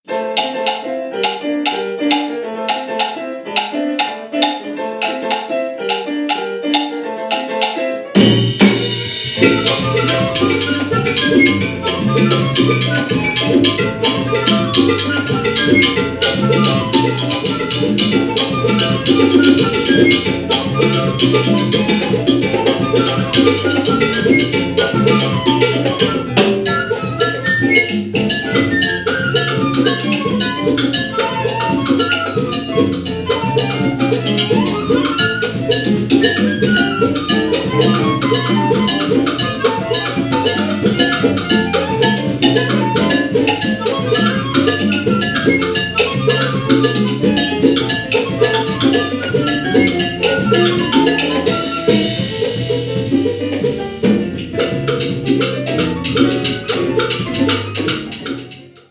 Afro-Cuban jazz
flutes
piano
bass
congas